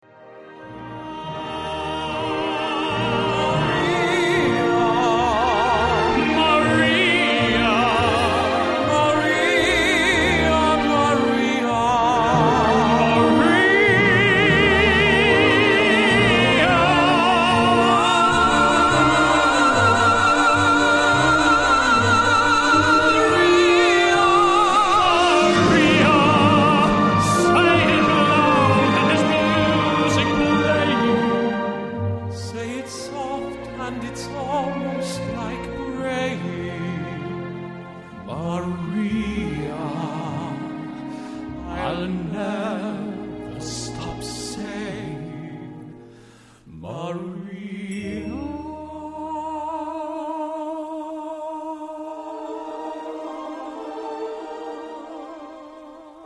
The Tenors is a performance that showcases the remarkable talents of two to three of Queensland’s premier tenors.
sophistication and beautiful melodies to the stage in a show to remember.